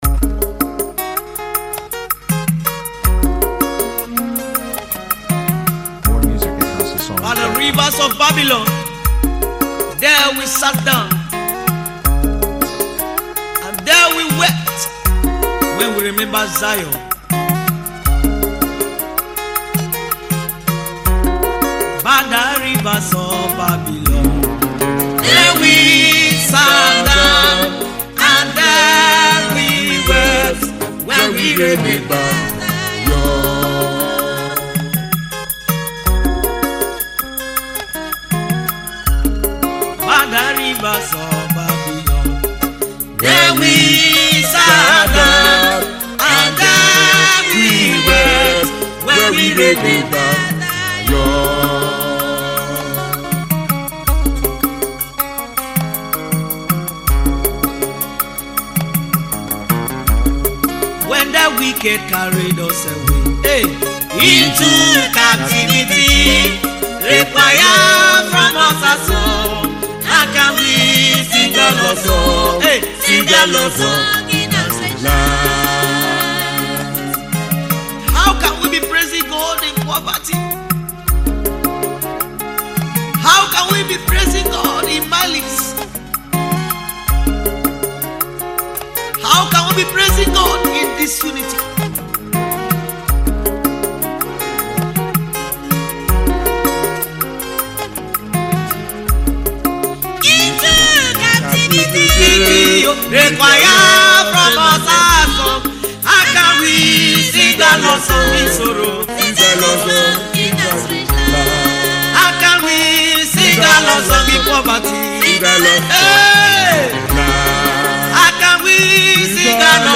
Isoko Gospel music